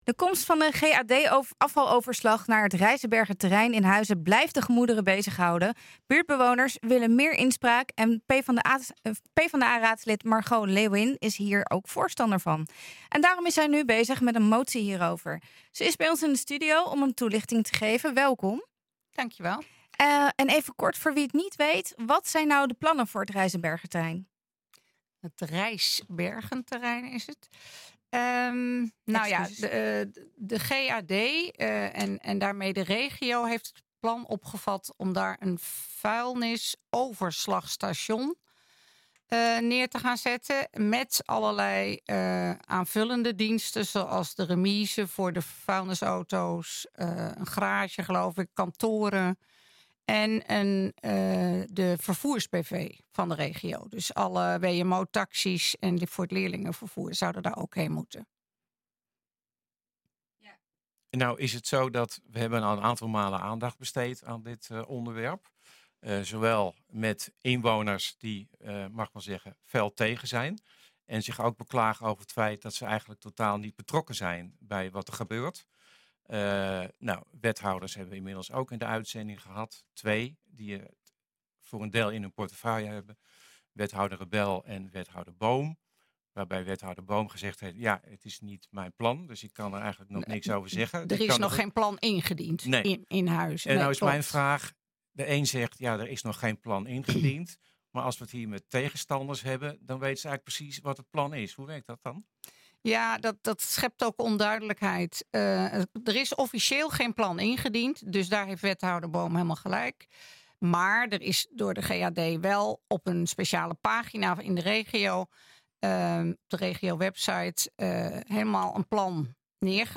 Ze is bij ons  in de studio om een toelichting te geven.